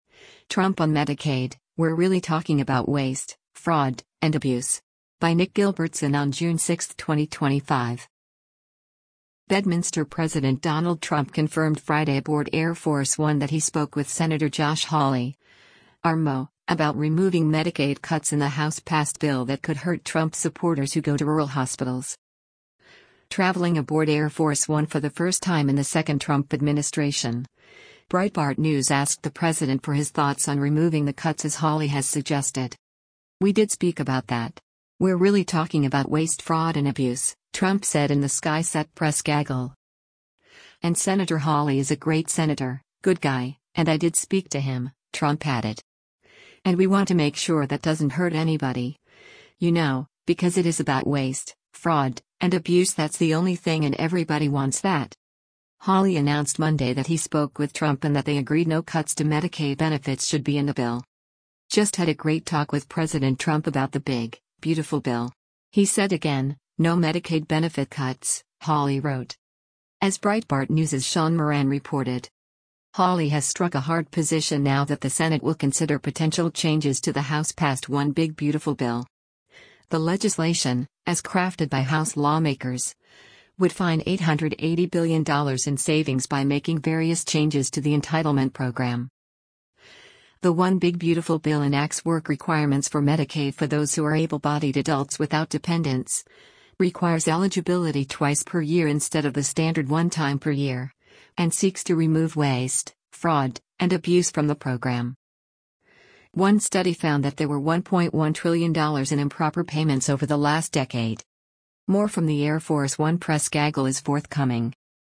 “We did speak about that. We’re really talking about waste fraud and abuse,” Trump said in the sky-set press gaggle.
More from the Air Force One press gaggle is forthcoming.